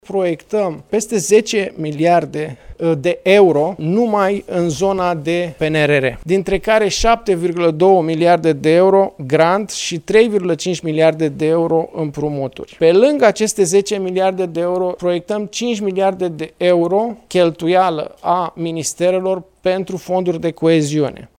Mai exact, prin investiții, cu bani de la stat, dar și din fonduri europene, a declarat la Palatul Victoria ministrul Finanțelor.
Ministrul Finanțelor, Alexandru Nazare: „Pe lângă aceste 10 miliarde de euro, proiectăm cinci miliarde de euro cheltuială a ministerelor pentru fonduri de coeziune”